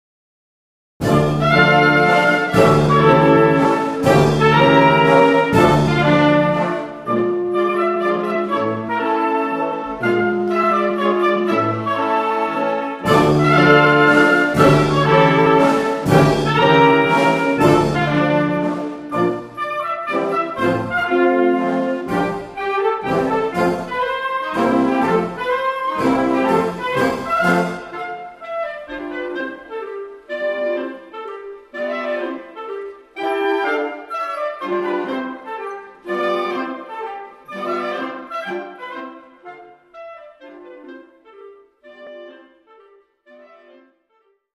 • Besetzung: Blasorchester